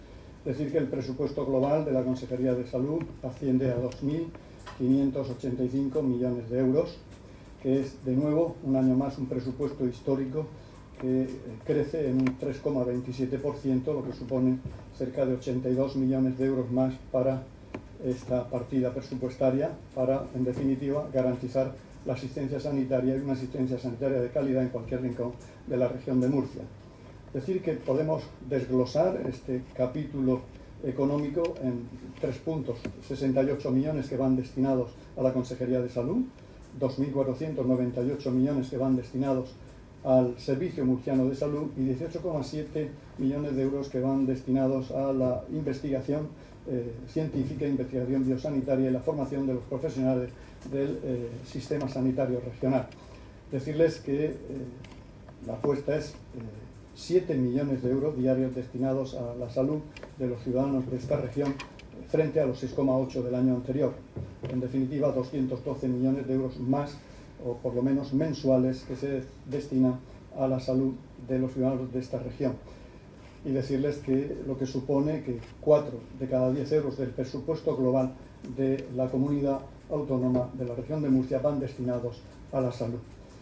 Sonido/ Declaraciones del consejero de Salud, Juan José Pedreño, en la presentación de los Presupuestos de Salud para 2025.
El consejero de Salud, Juan José Pedreño, presentó hoy en la Asamblea Regional el proyecto del presupuesto destinado a Sanidad acompañado de su equipo.